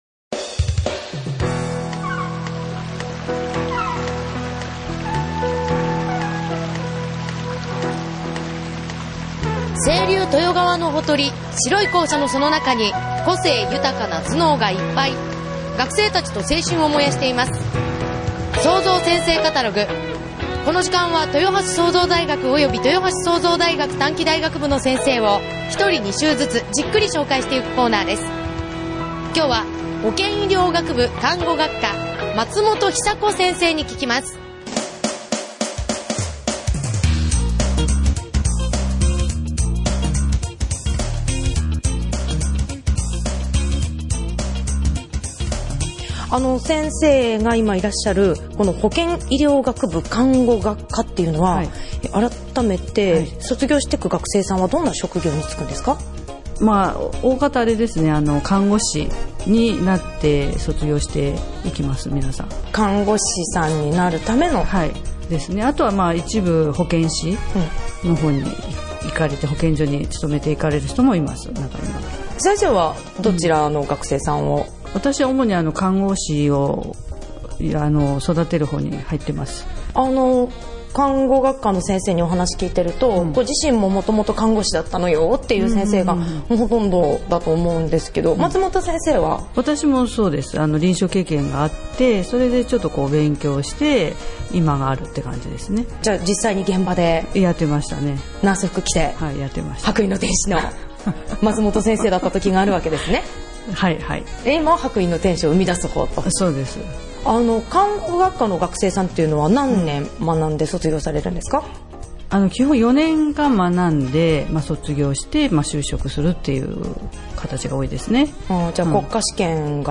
5月 22日 インタビュー前半 5月 29日 インタビュー後半 ※エフエム豊橋「SOZO’s先生カタログ」毎週月曜日 15：30より